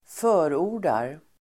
Uttal: [²f'ö:ro:r_dar]